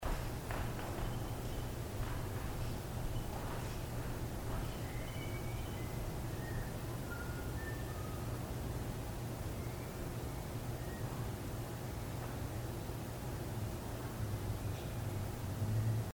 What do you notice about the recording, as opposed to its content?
On October 11-12, 2014 the Public Information Office and the Morton Theatre staff brought in a team from Ghosts of Georgia Paranormal Investigations to investigate strange occurrences that have been reported by numerous people throughout the building's history.